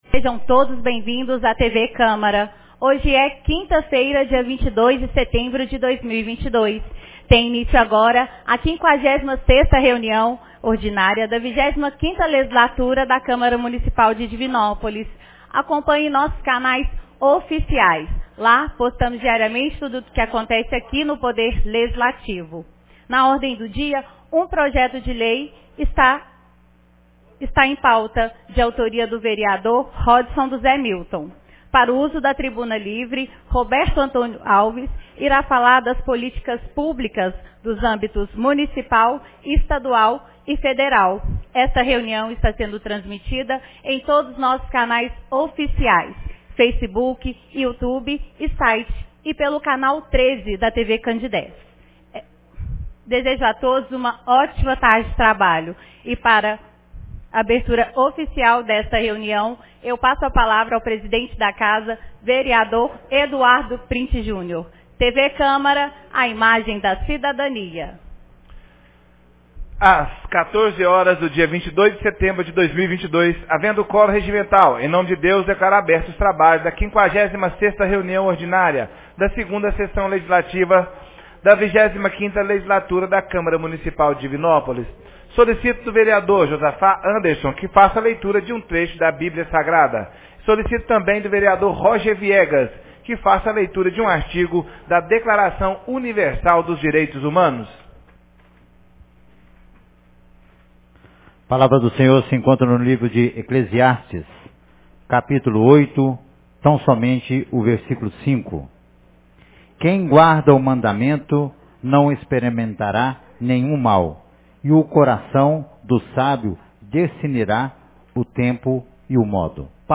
56ª Reunião Ordinária 22 de setembro de 2022